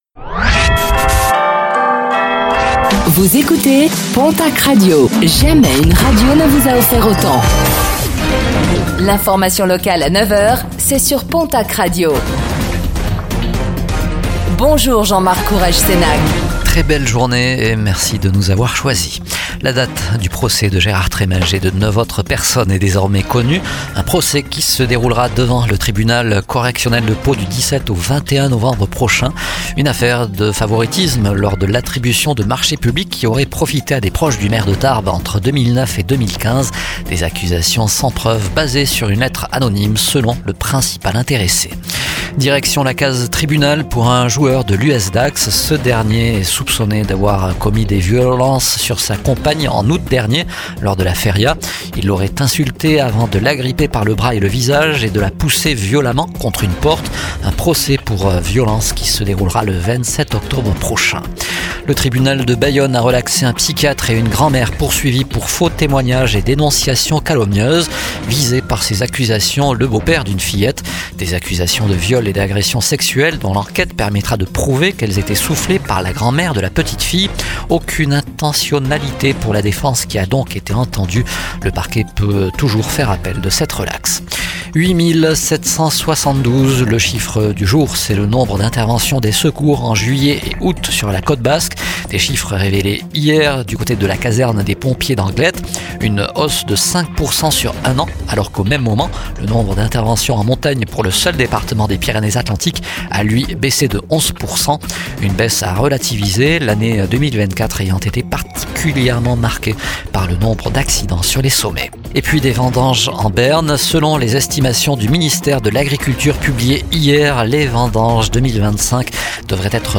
09:05 Écouter le podcast Télécharger le podcast Réécoutez le flash d'information locale de ce mercredi 08 octobre 2025